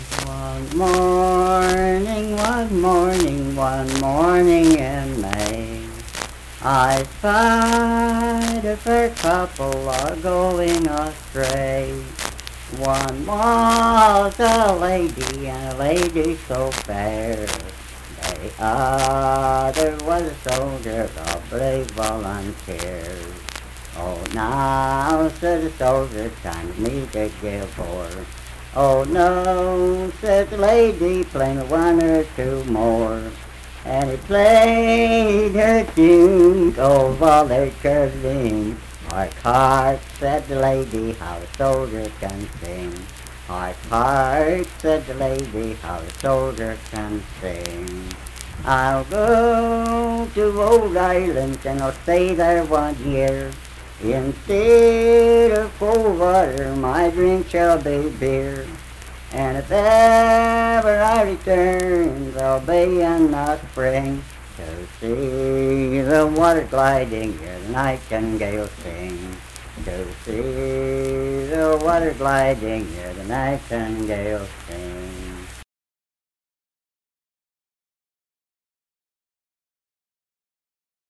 Unaccompanied vocal music performance
Verse-refrain 3(4-5w/R).
Voice (sung)